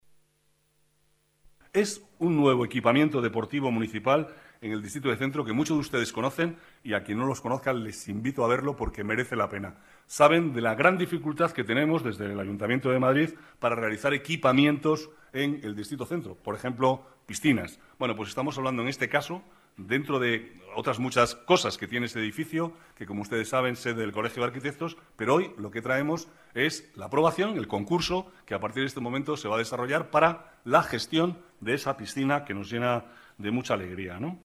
Nueva ventana:Declaraciones del vicealcalde, Manuel Cobo: Centro Deportivo Escuelas Pías